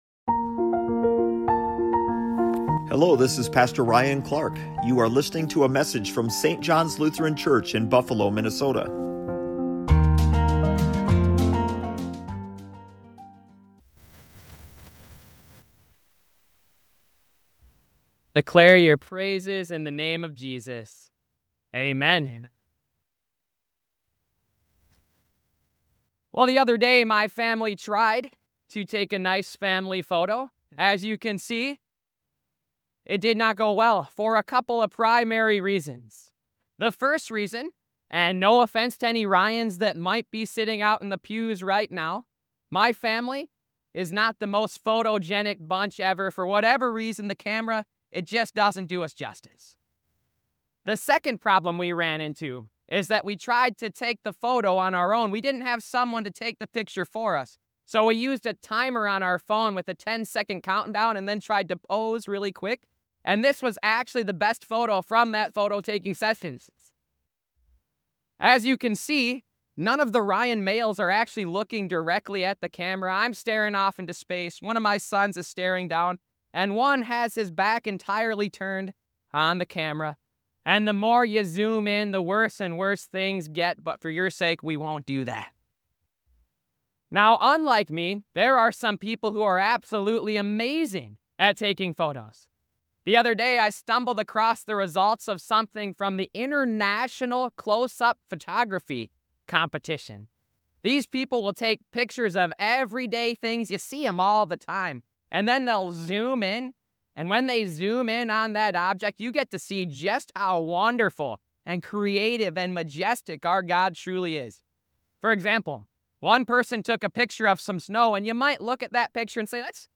📖 The promise of eternal life is often overlooked or overly familiar - but there is SO MUCH to look forward to - and NOT just in the hereafter. Don't miss this encouraging sermon from St. John's Lutheran Church!